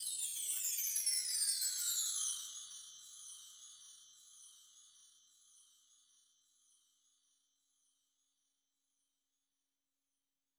04 chimes 2 hit.wav